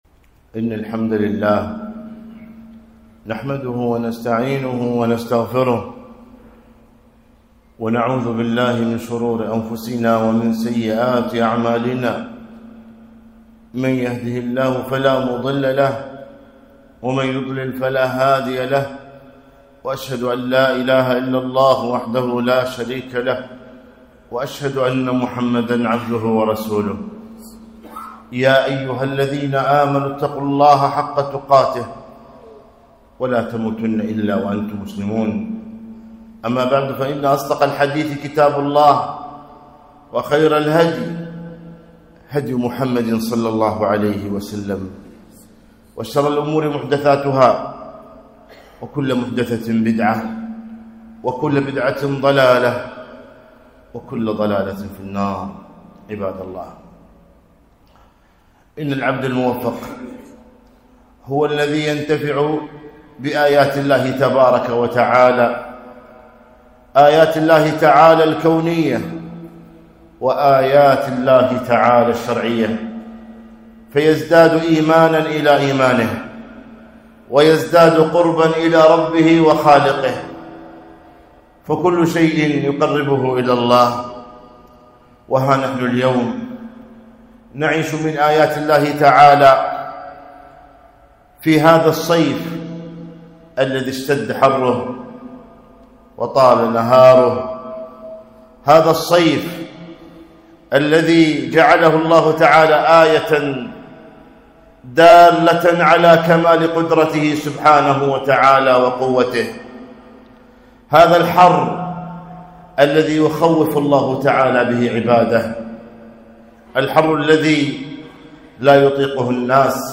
خطبة - العبرة بحرارة الصيف